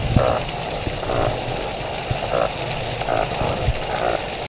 海の生き物の鳴き声を聞こう
2019年1月号の記事「海の生き物は鳴いている」では、海洋生物が種によってさまざまな音を発していることを紹介したよ。